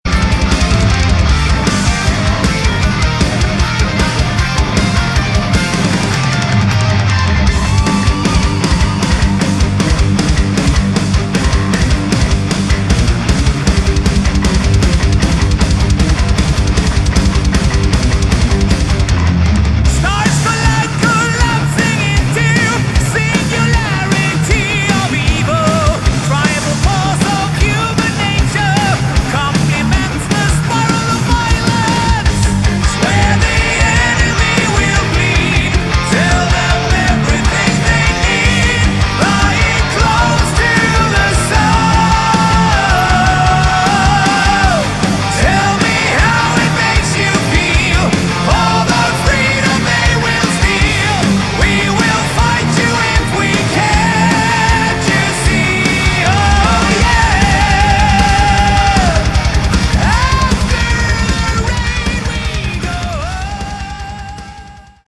Category: Hard Rock
vocals
rhythm, lead, and acoustic guitars
bass
drums, percussion